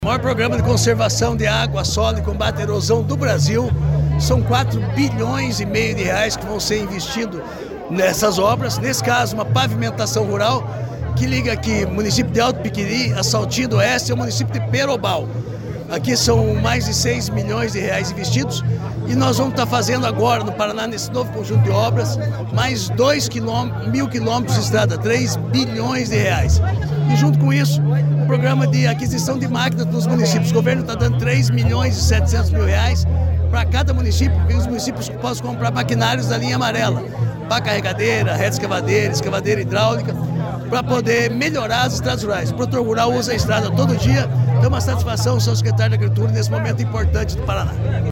Sonora do secretário da Agricultura e do Abastecimento, Márcio Nunes, sobre a pavimentação que vai facilitar escoamento agrícola em Alto Piquiri